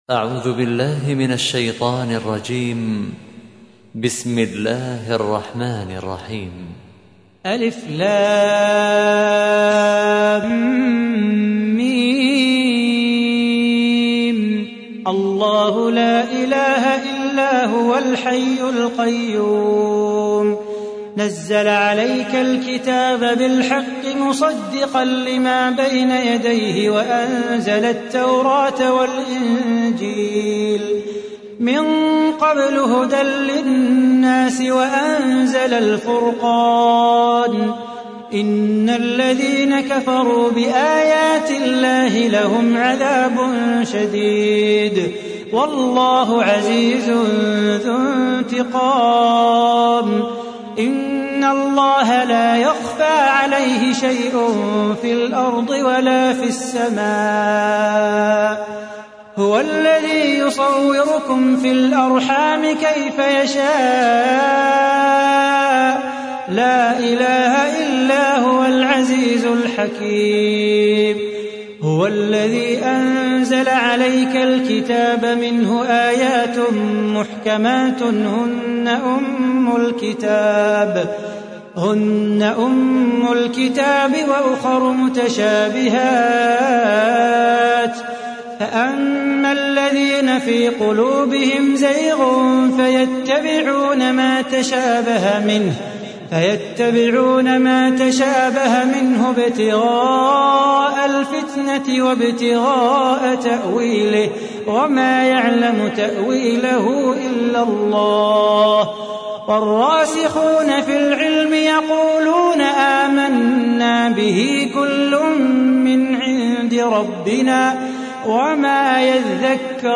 سورة آل عمران / القارئ